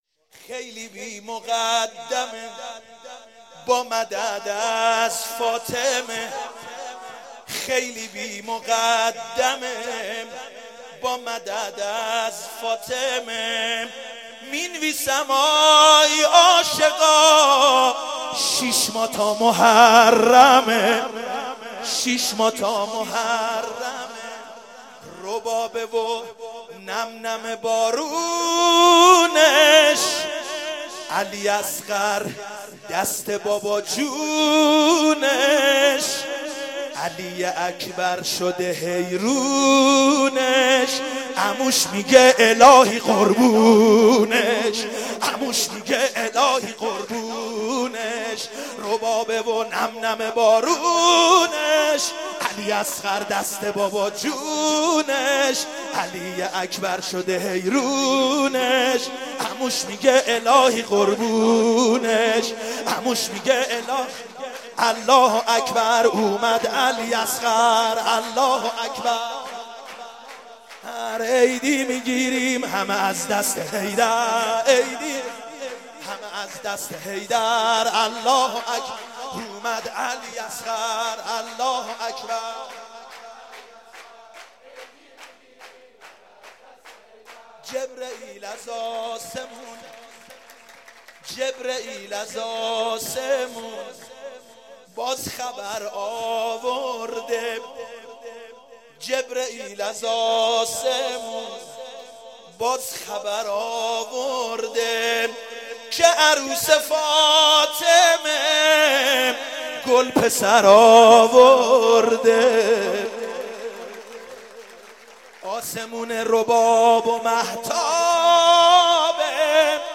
ولادت شور